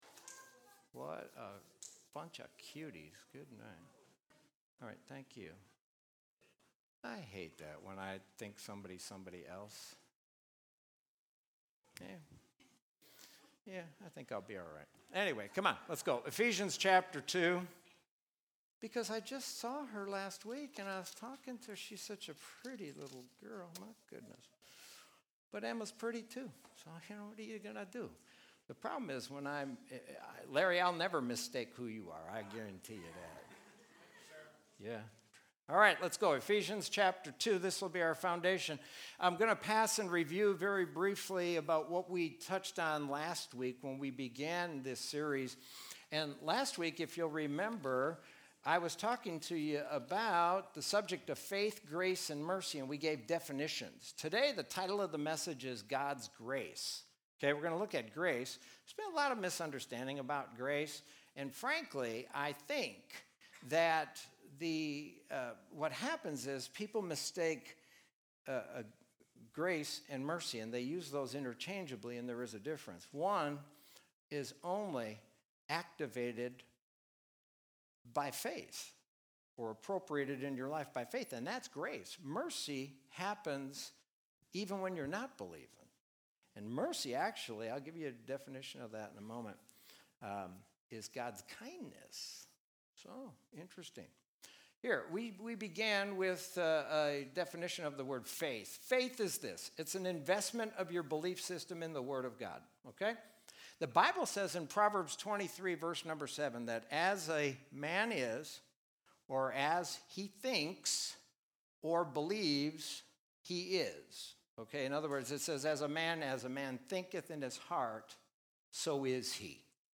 Sermon from Sunday, June 14th, 2020.